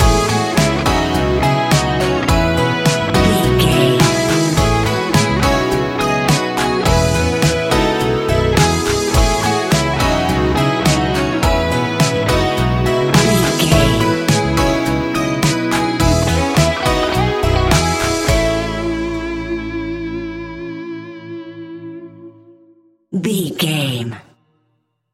Ionian/Major
ambient
electronic
new age
downtempo
pads